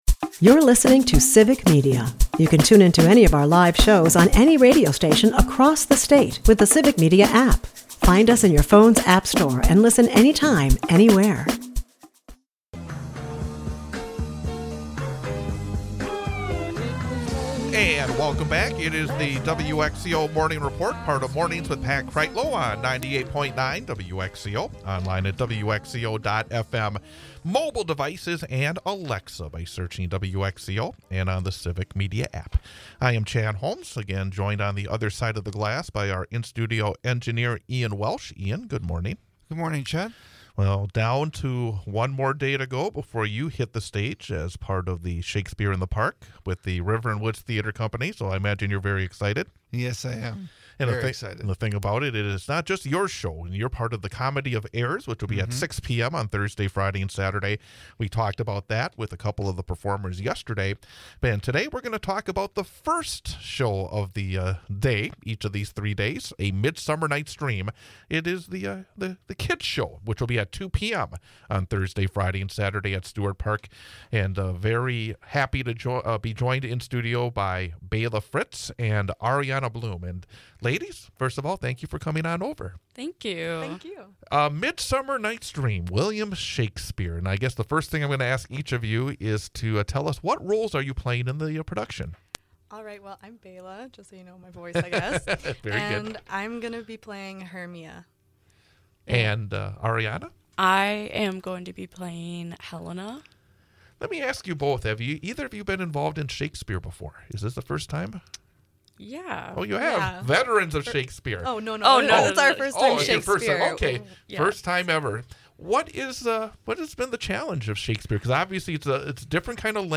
wxco news